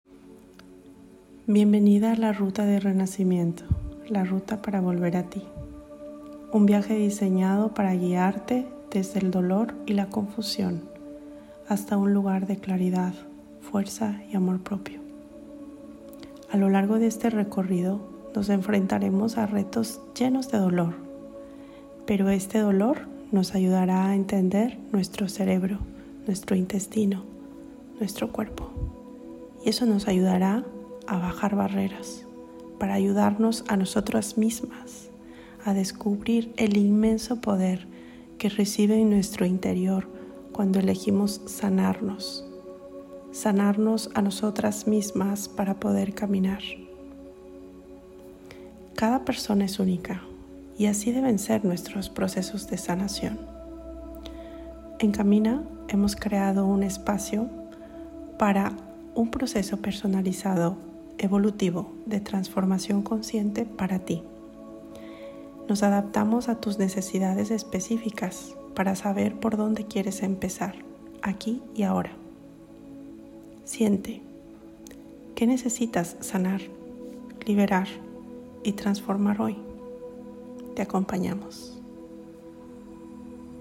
Meditaciones Sanadoras